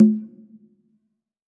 PCONGA LW.wav